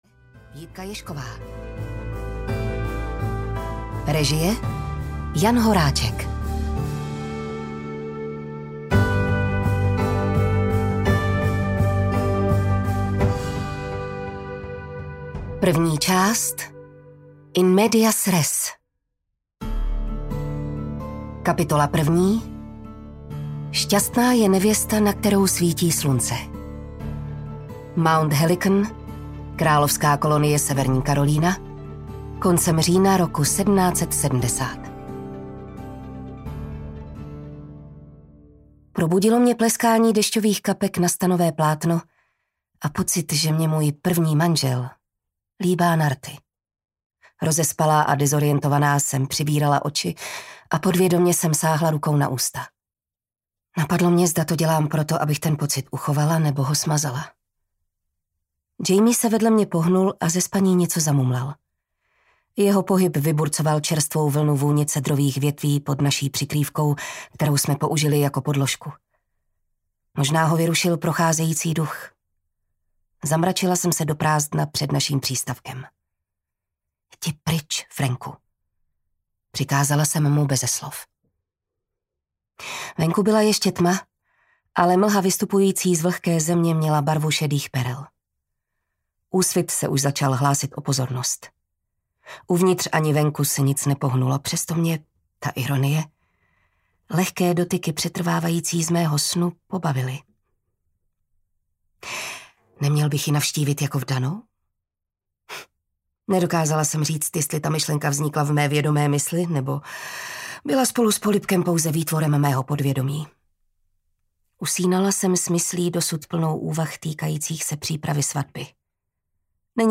Hořící kříž audiokniha
Ukázka z knihy
• InterpretJitka Ježková